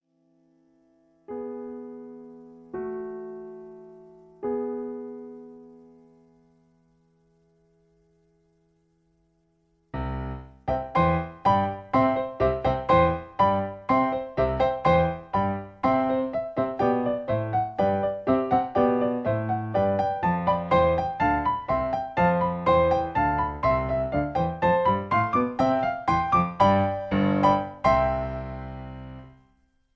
Original Key (A). Piano Accompaniment